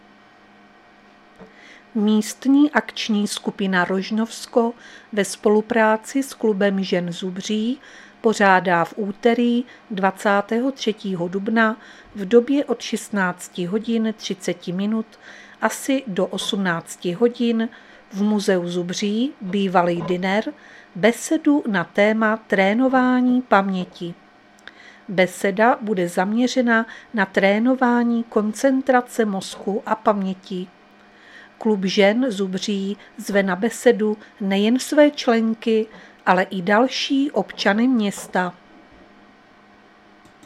Záznam hlášení místního rozhlasu 22.4.2024
Zařazení: Rozhlas